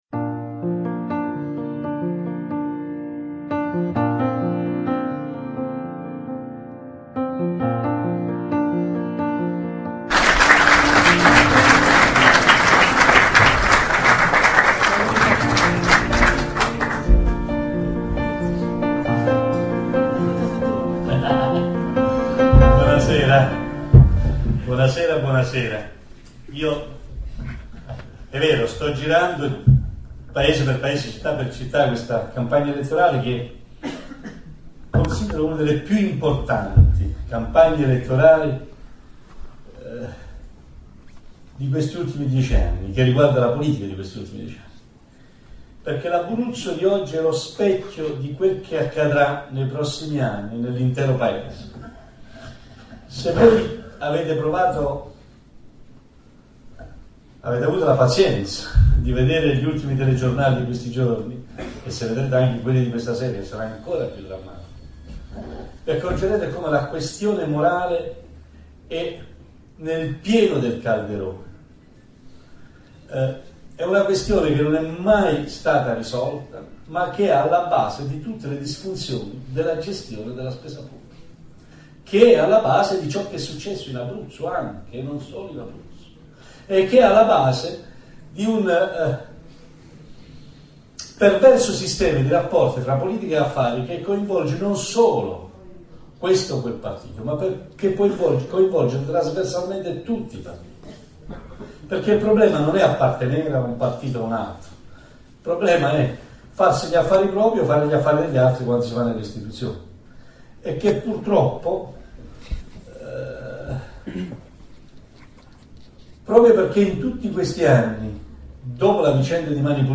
Antonio Di Pietro, Mosciano (TE), Teatro Acquaviva. 4 dicembre 2008
ammazza, il teatro era pieno,